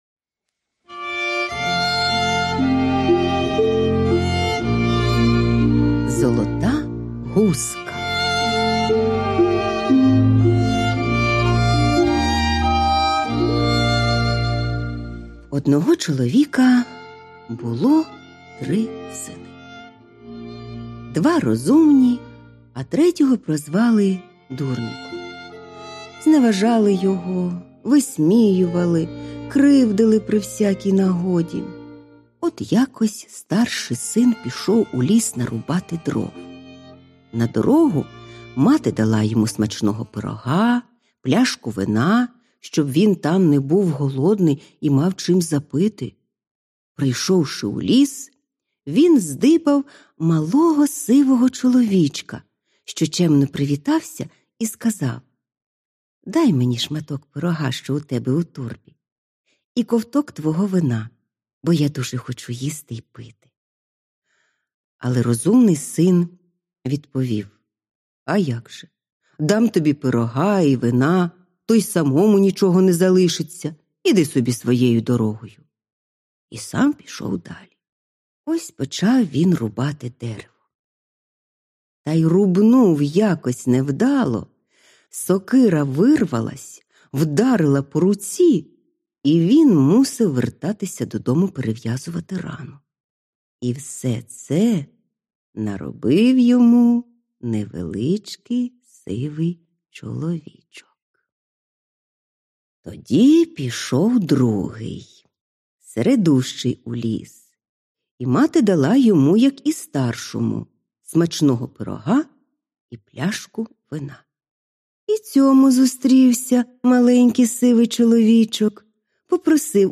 Аудіоказка Золота гуска